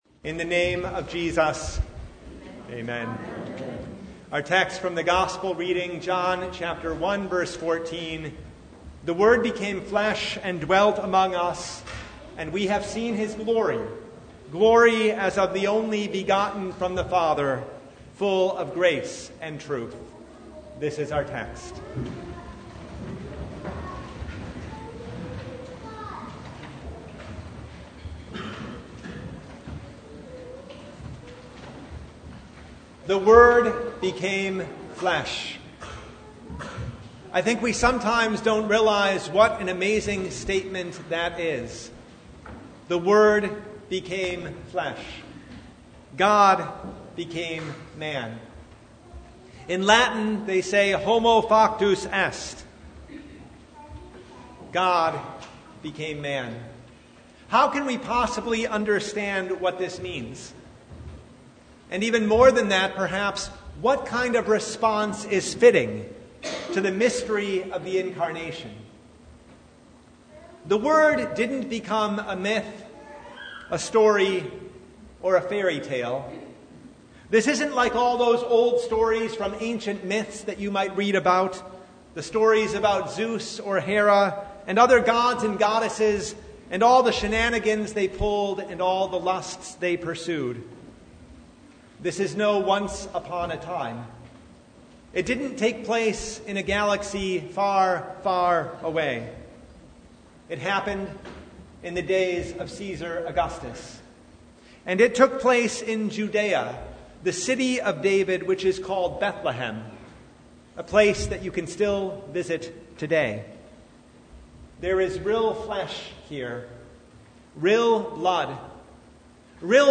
Service Type: Christmas Day